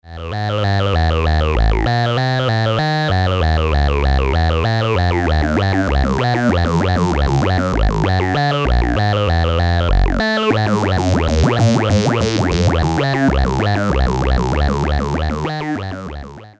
Formant sound.
phaser_formant.mp3